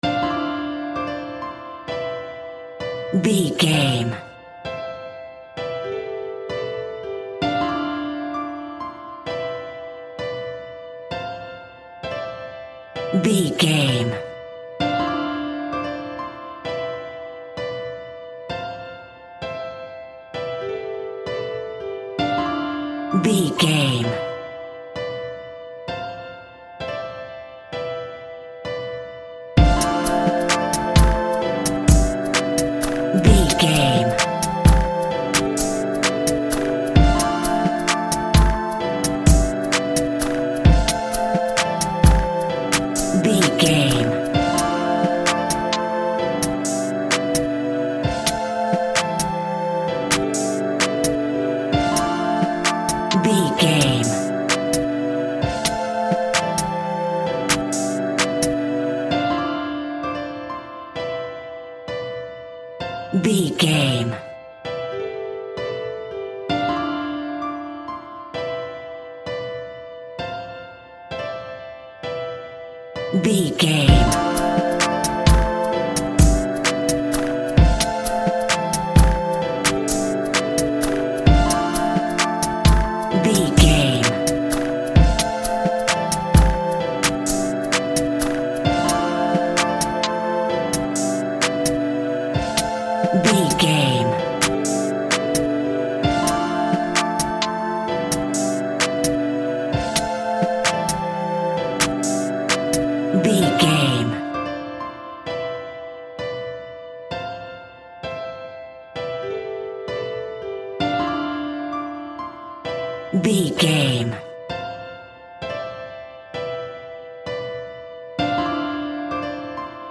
Ionian/Major
drums
mellow
soothing
urban